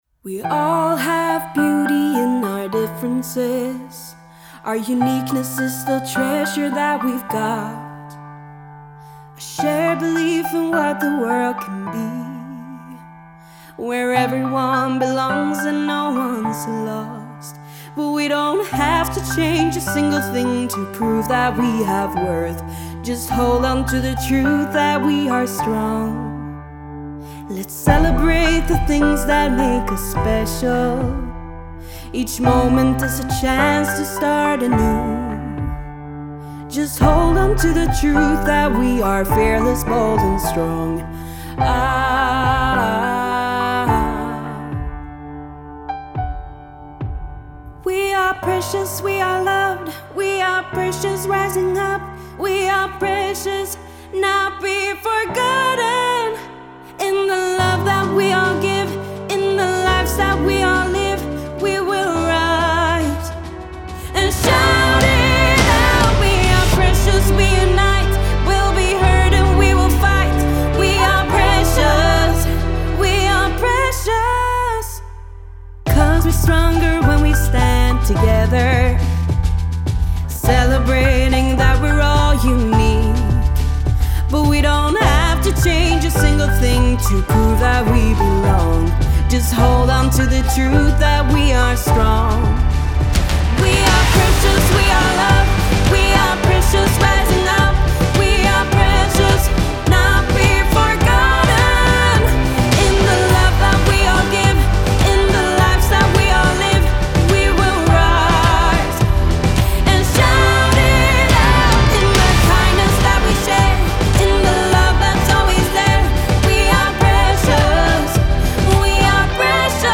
a grand musical manifestation for rights and equality.
a powerful and beautiful anthem of strength and unity